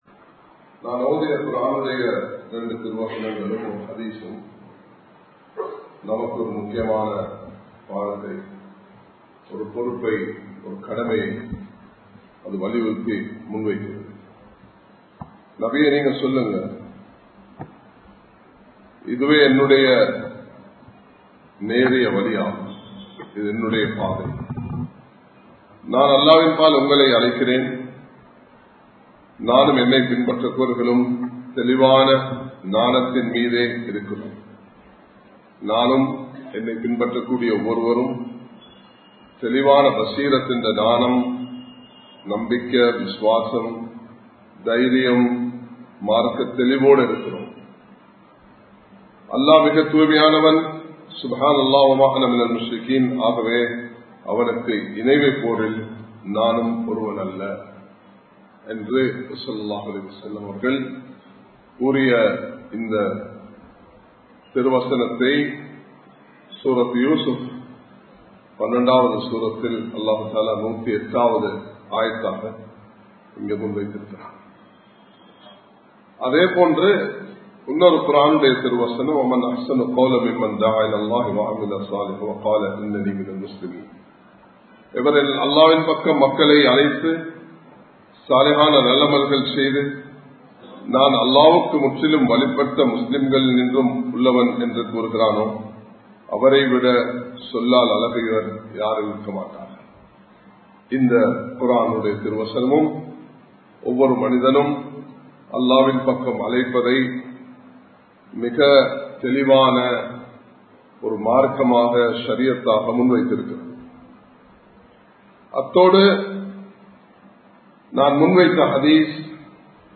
அல்லாஹ்வின் பக்கம் திரும்புவோம் | Audio Bayans | All Ceylon Muslim Youth Community | Addalaichenai
Samman Kottu Jumua Masjith (Red Masjith)